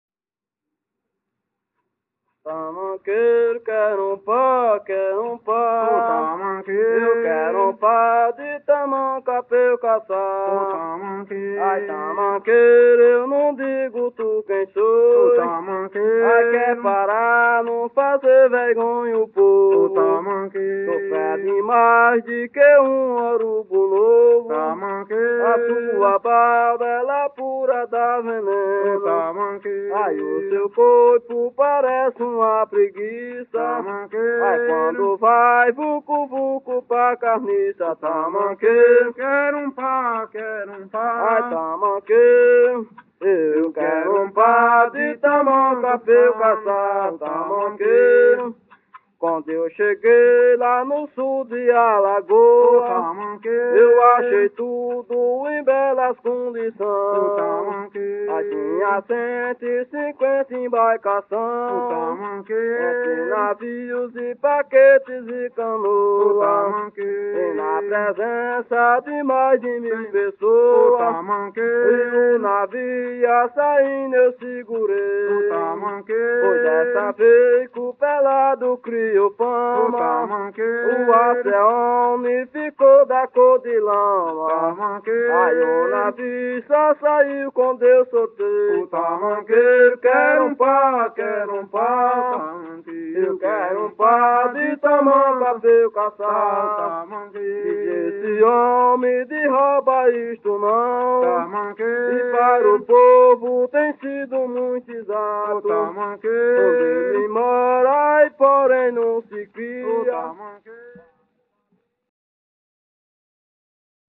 Coco de parelha - ""Eu quero um par""